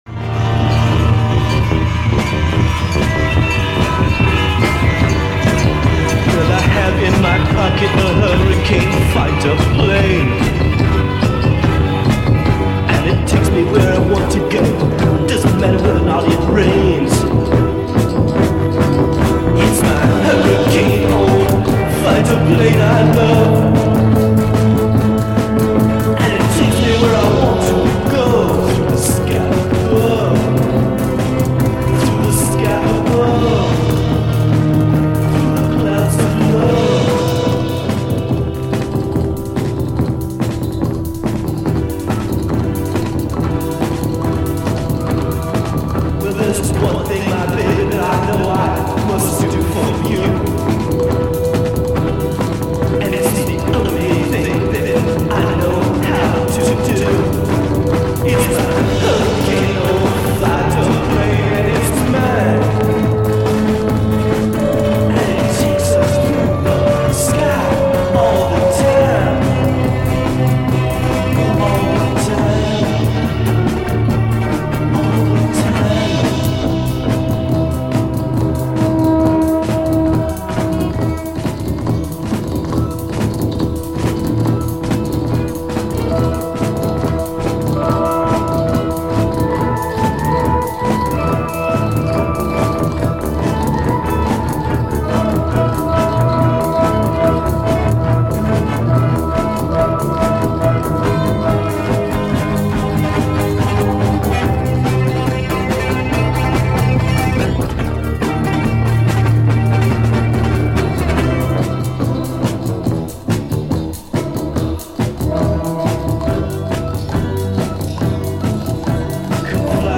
psychedelic album
playing organ
full-sounding guitar/bass/drums/vocals rock sound